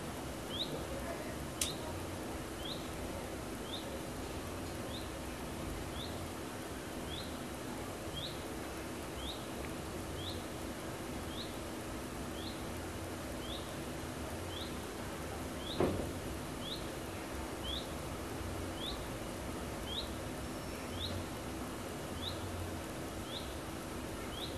Sinds afgelopen weekend zit er hier in de buurt een ontzettend vervelend vogeltje bijna de hele dag door monotoon "tjiep, tjiep, tjiep" te doen. Geen enkele vorm van melodie, erg monotoon.
MP3tje van het getjiep
vogeltje.mp3